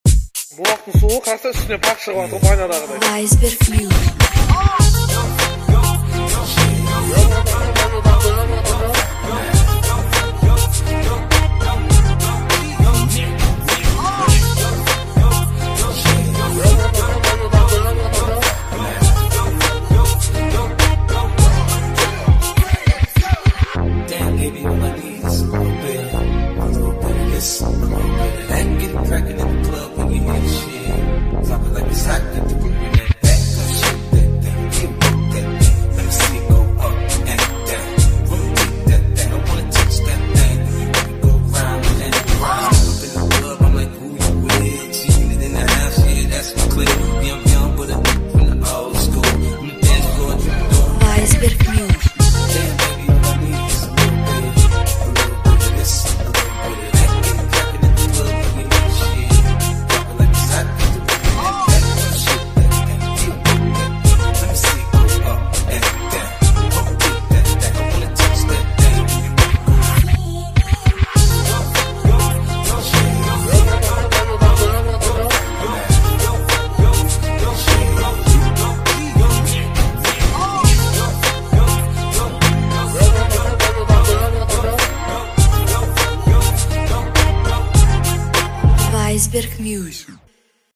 ДИНАМИЧНАЯ МУЗЫКА ВОСТОРЖЕННЫЕ ВОЗГЛАСЫ